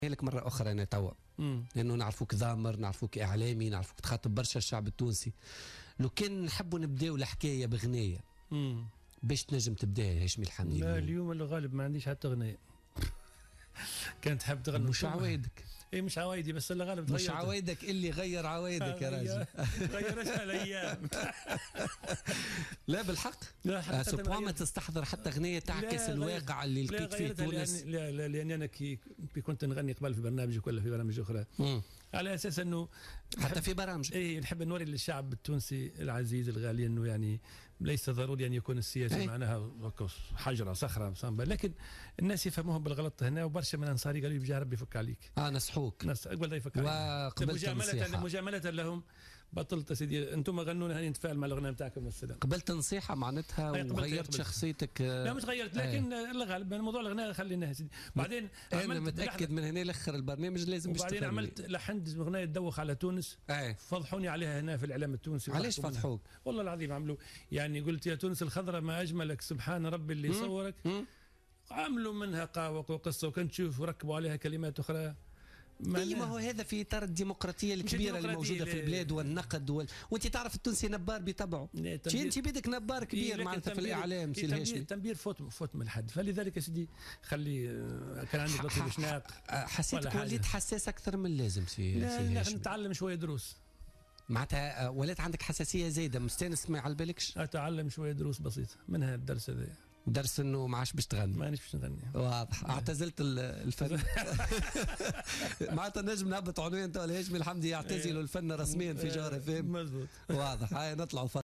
رفض الهاشمي الحامدي رئيس تيار المحبة ضيف بولتيكا اليوم الإثني الغناء مؤكدا أنه اعتزله نهائيا أخذا بنصائح بعض المقربين منه.